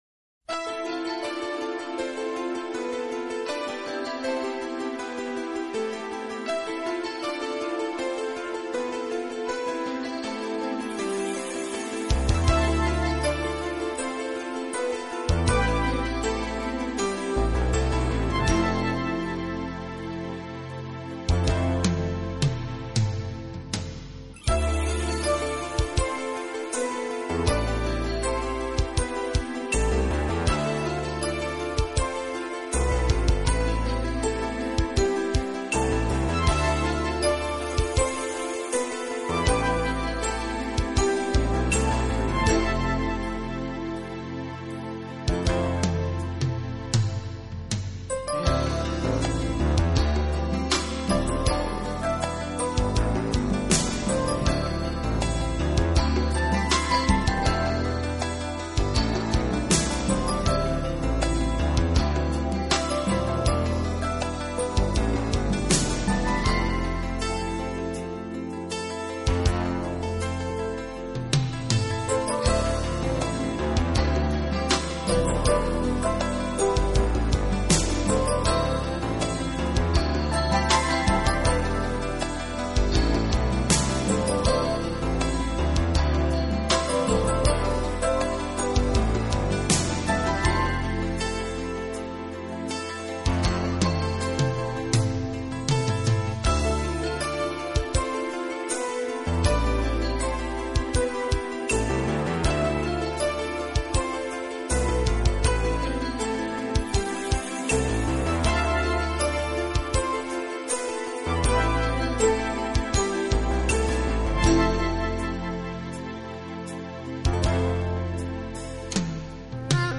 爵士钢琴